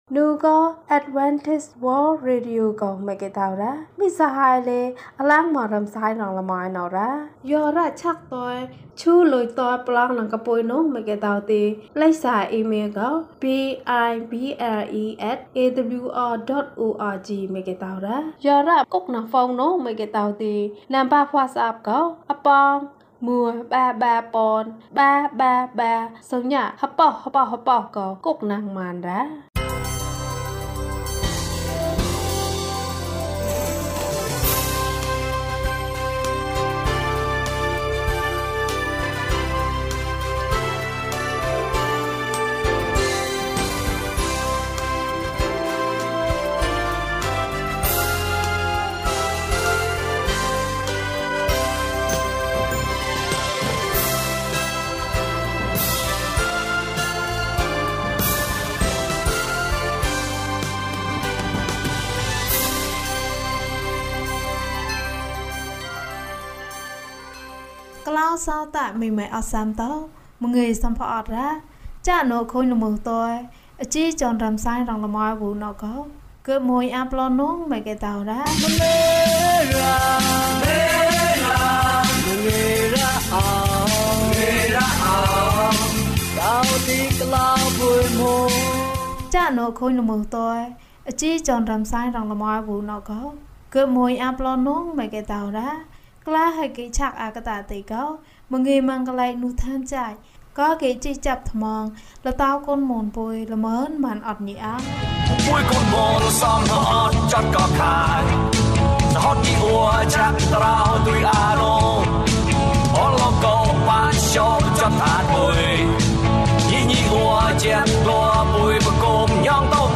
ဝတ်ပြုရေးနှင့် ကျန်းမာရေး ဟောပြောပွဲ။ ဓမ္မသီချင်း။ တရားဒေသနာ။